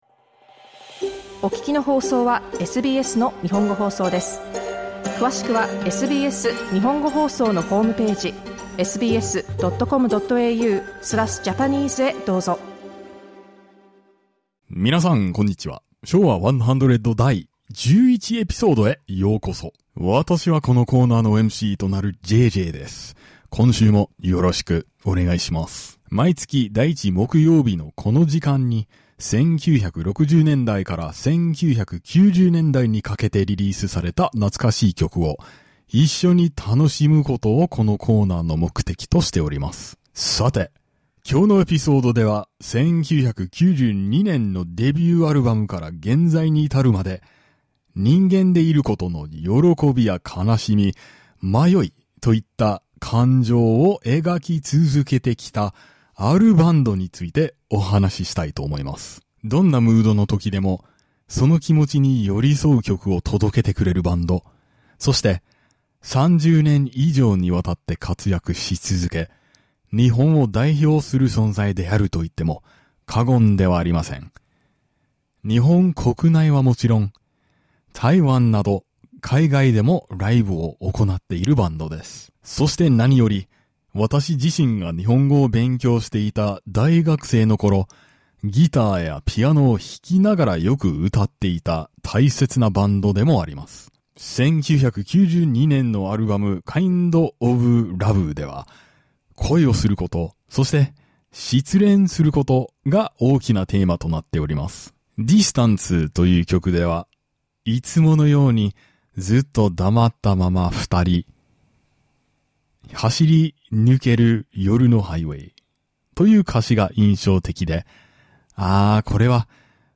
Let's look back on that era while enjoying nostalgic songs from the 1960s to the 1990s. 'Showa 100' is broadcast on the first Thursday of every month.